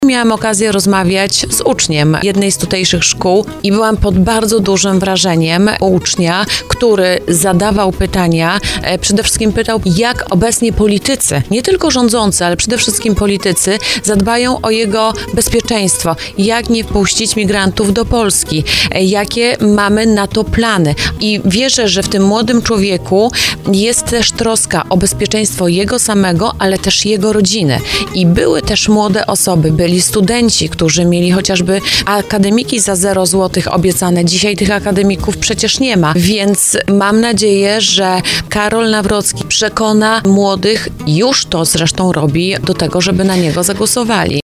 O rosnącym poparciu dla Nawrockiego mówiła dziś na antenie RDN Małopolska poseł PiS Anna Pieczarka, która była gościem programu Słowo za Słowo.